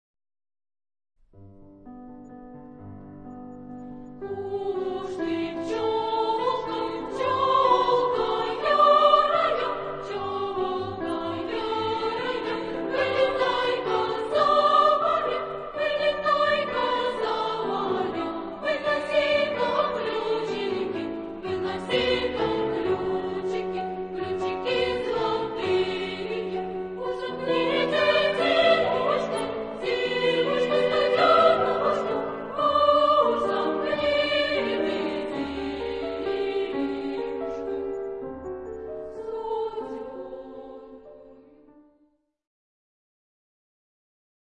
SAA (3 voices children) ; Choral score with piano.
Secular ; Partsong Mood of the piece: tender ; warm Type of Choir: SAA (3 children voices ) Instruments: Piano (1)
Tonality: G major